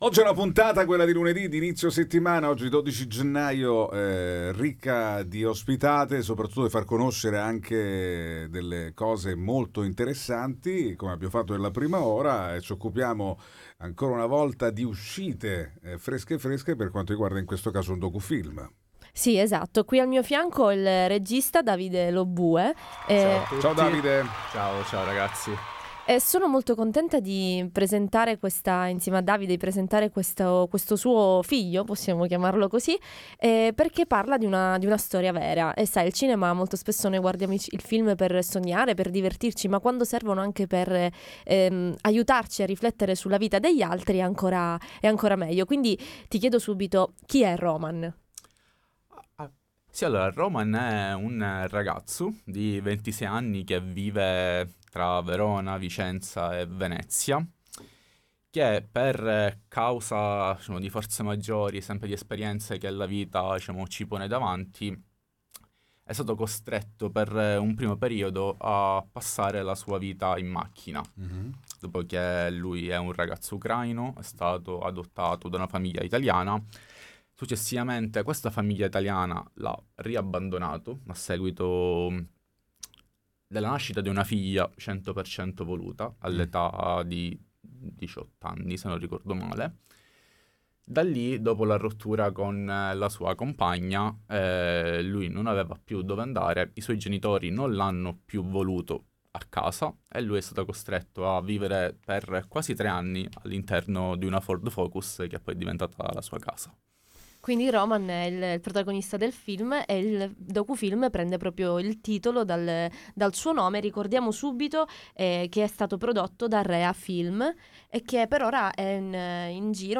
All Inclusive Interviste 12/01/2026 12:00:00 AM